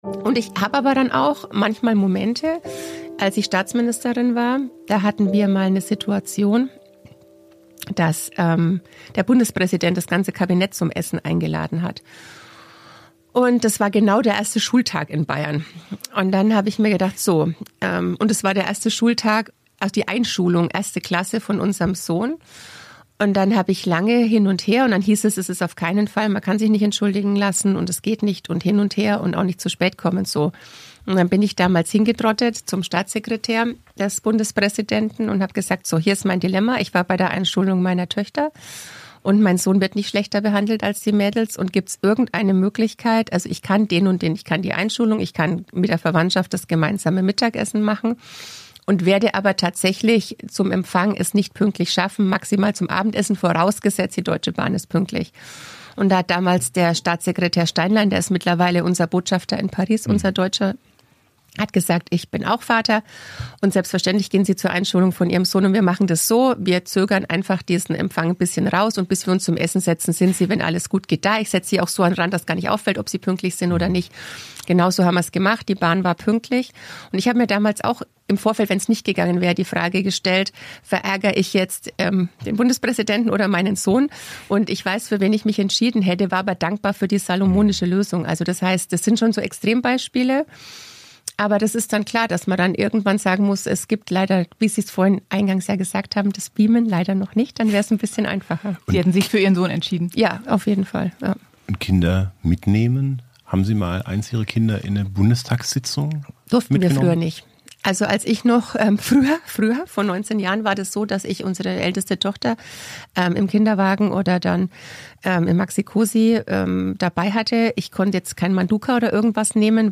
Spitzenpolitikerinnen und -politiker im Gespräch